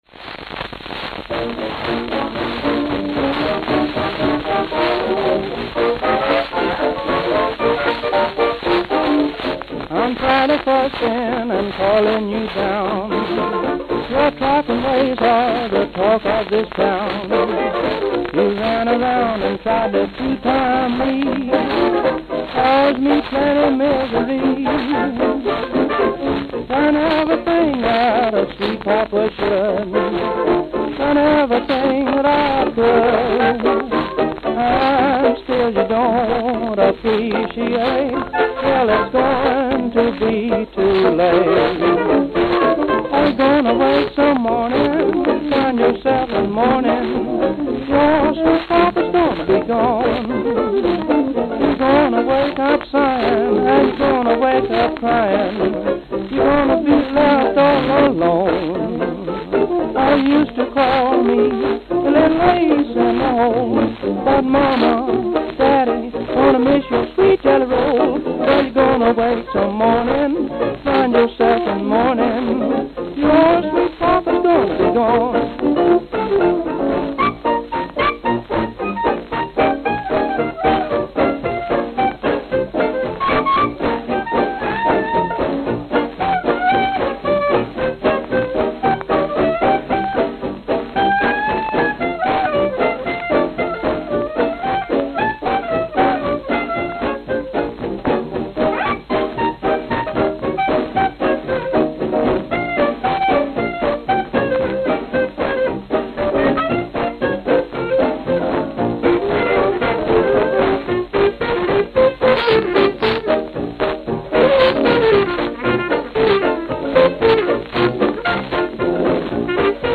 San Antonio, Texas San Antonio, Texas
Note: Extremely worn.